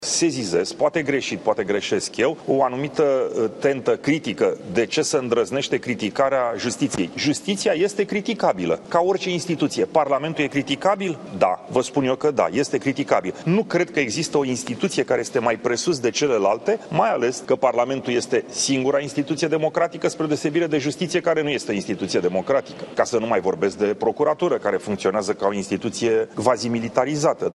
Călin Popescu Tăriceanu a făcut această afirmație într-o conferință de presă în care le explica jurnaliștilor ce presupune imunitatea parlamentarilor.